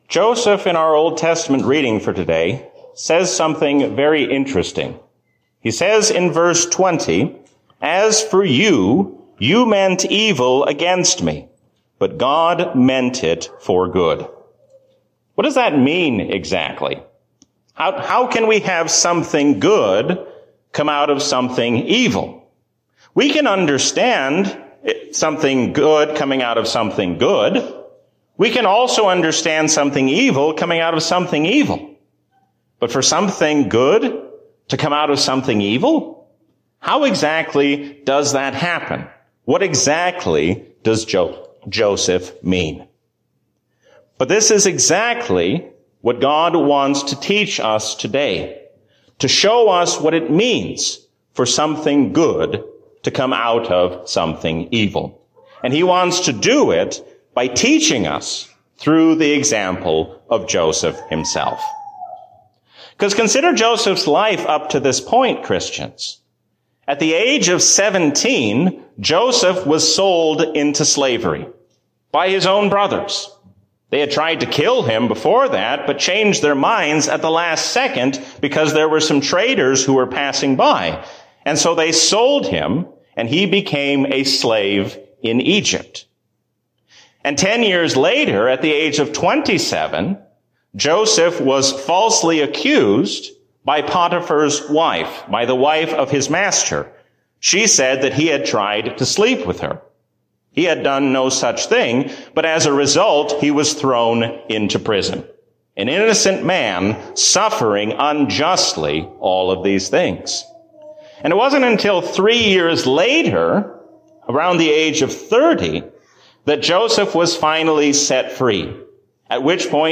A sermon from the season "Trinity 2021." Christ's righteousness is your righteousness.